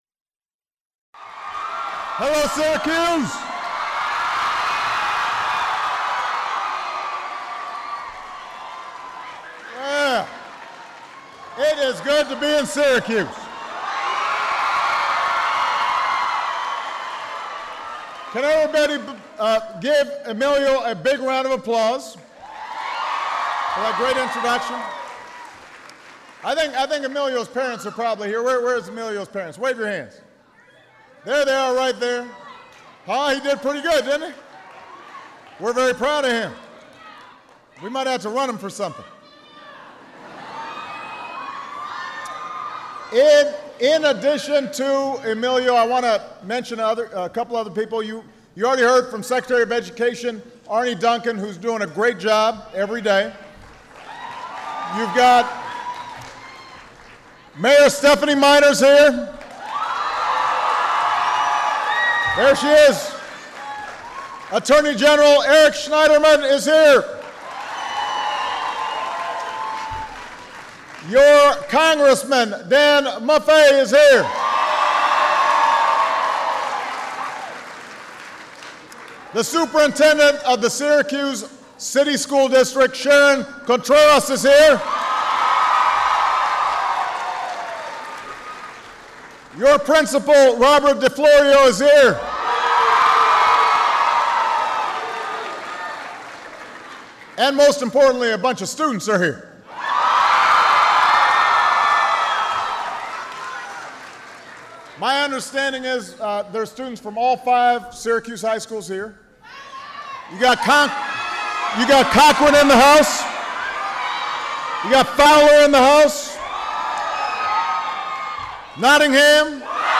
President Obama discusses his plans to make college more affordable, tackle rising costs, and improve value for students and their families. Held at Henninger High School in Syracuse, New York.